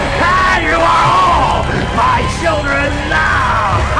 ROBERT (AS FREDDY)